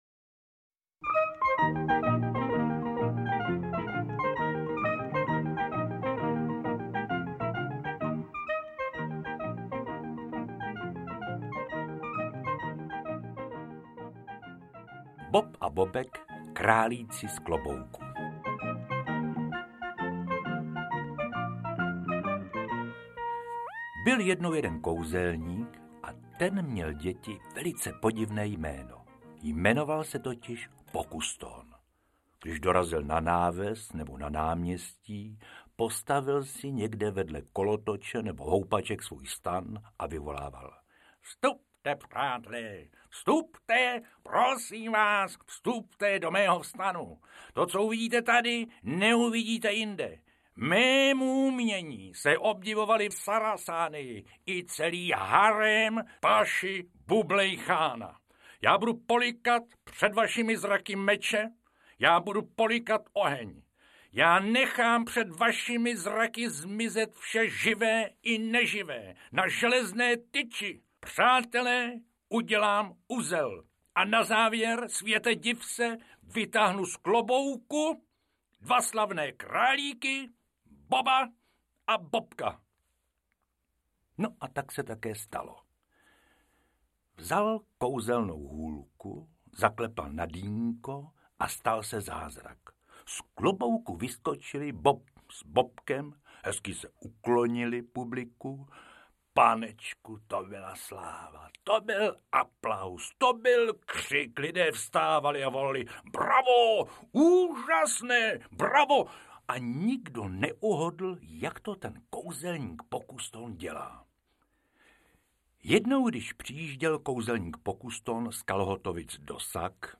Bob a Bobek, králíci z klobouku / Šebánek - Pacovský - Jiránek - Jaroslav Pacovský, Jiří Šebánek, Vladimír Jiránek - Audiokniha
• Čte: Josef Dvořák